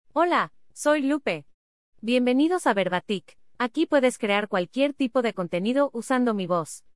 Lupe — Female US Spanish AI voice
Lupe is a female AI voice for US Spanish.
Voice sample
Listen to Lupe's female US Spanish voice.
Lupe delivers clear pronunciation with authentic US Spanish intonation, making your content sound professionally produced.